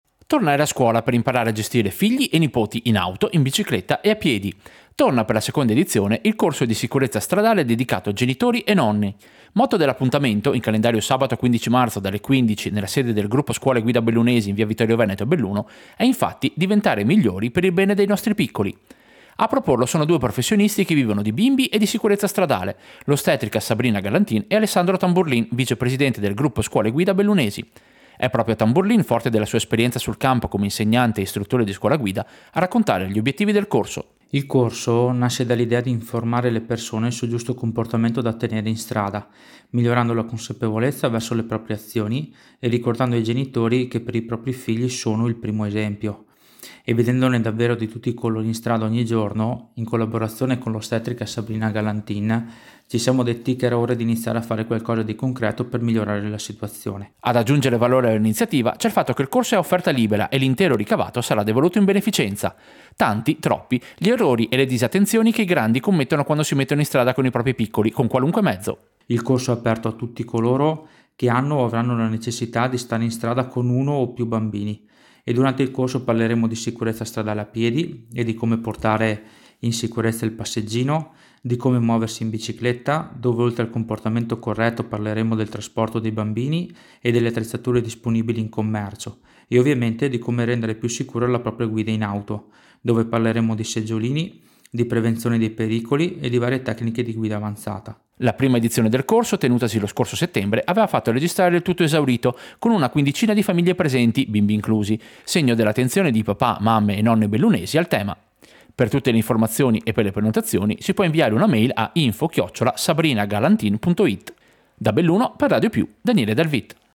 Servizio-Corso-genitori-in-strada.mp3